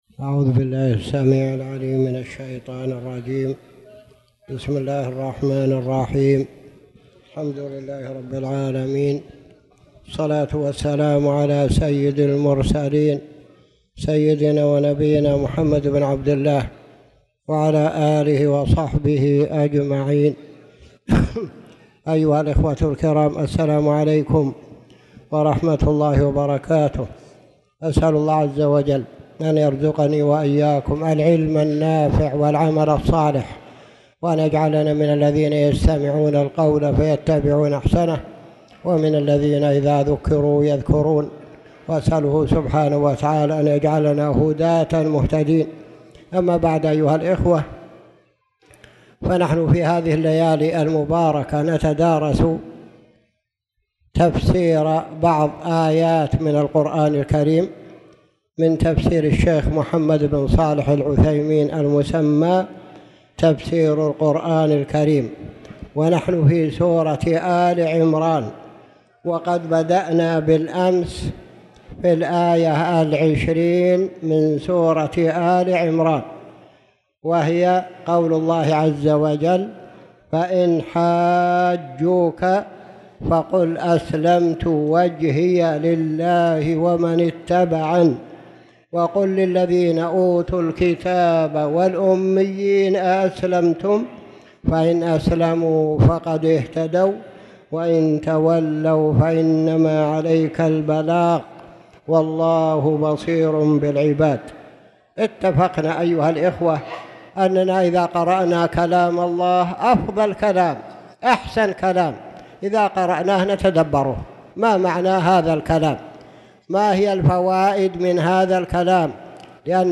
تاريخ النشر ٢٤ ربيع الثاني ١٤٣٨ هـ المكان: المسجد الحرام الشيخ